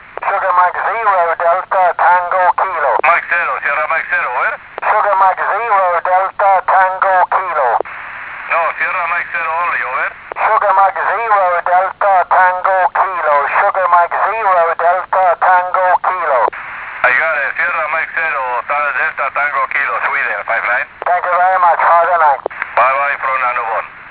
QSO with 3C0BYP in Annabon Island 25 February 2014 at 13.16 Z.